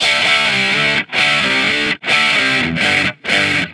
Guitar Licks 130BPM (2).wav